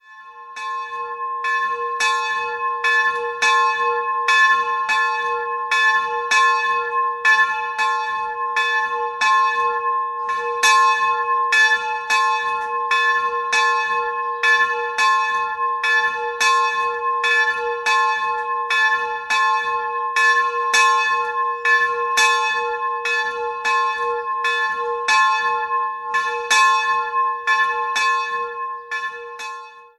Die Glocke der Kapelle Hl. Dreifaltigkeit in Oberbürg
Klicken Sie hier, um das Geläut anzuhören: